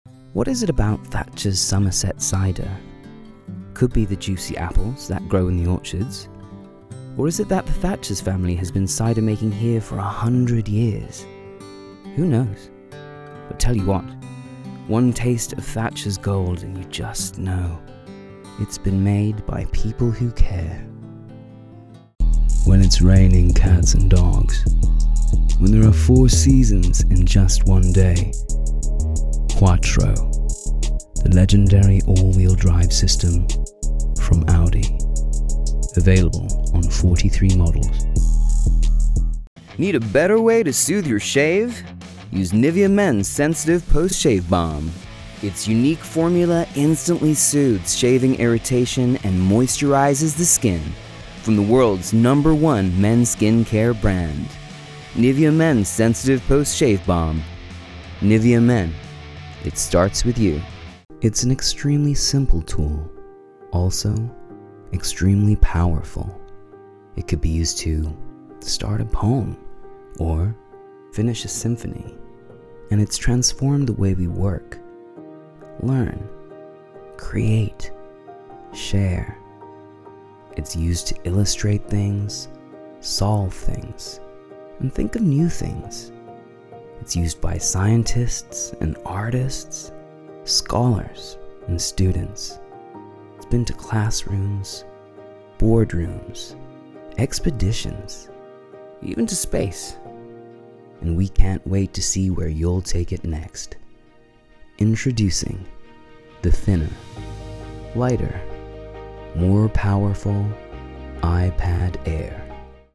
Commercial Showreel
Male
American Standard
British RP
Confident
Friendly
Youthful
Warm
Upbeat